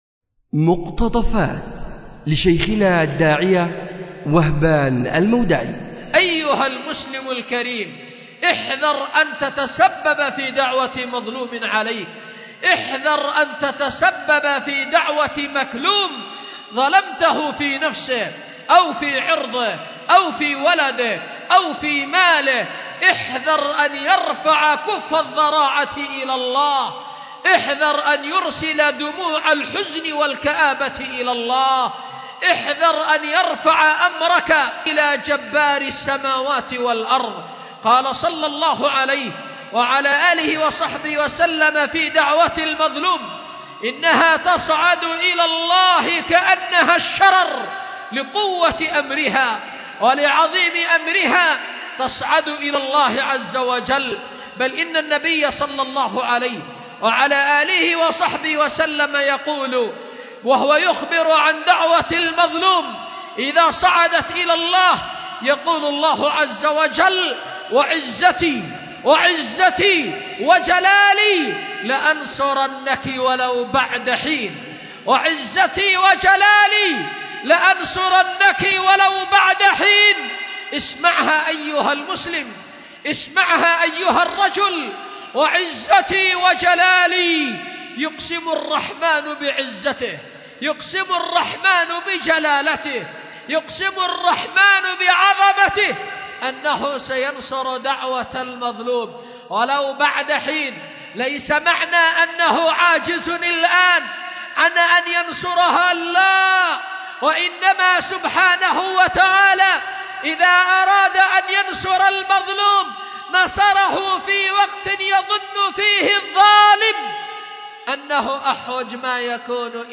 أُلقي بدار الحديث للعلوم الشرعية بمسجد ذي النورين ـ اليمن ـ ذمار ـ 1444هـ